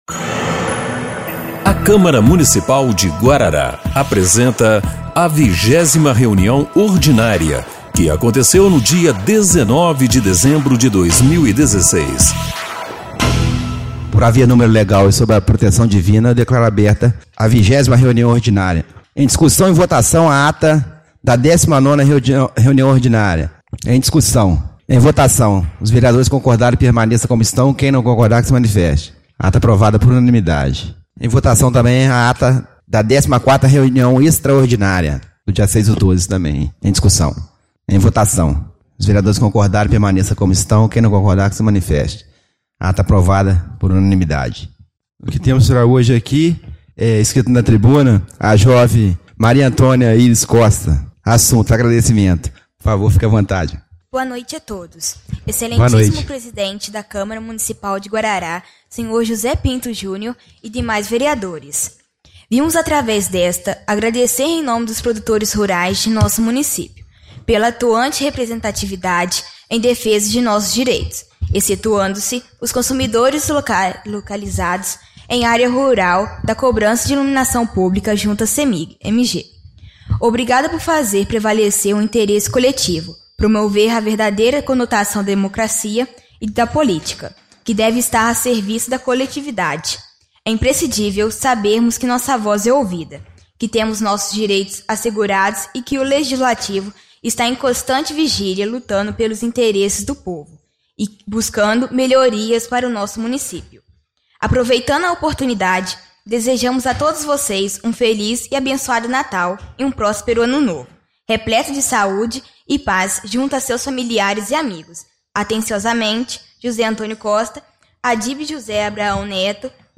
20ª Reunião Ordinária de 19/12/2016